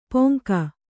♪ poŋka